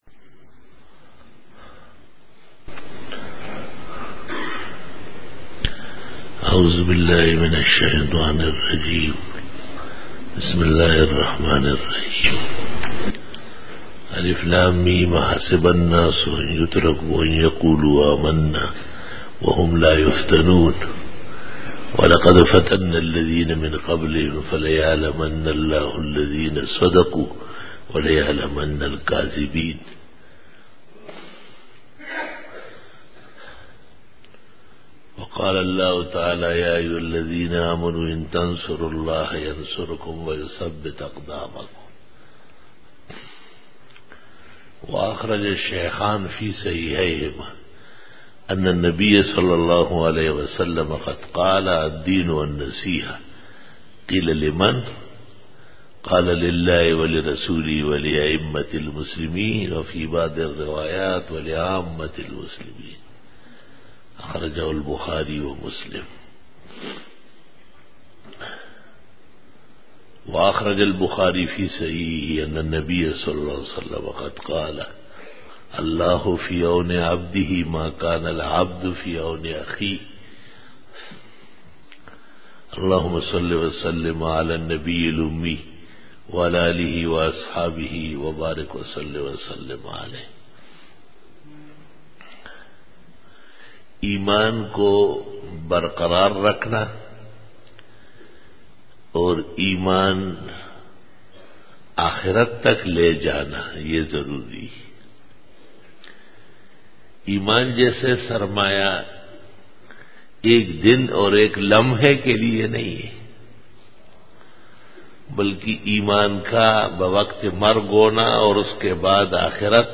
06 Bayan e juma tul mubarak 6-feburary-2013
Khitab-e-Jummah 2013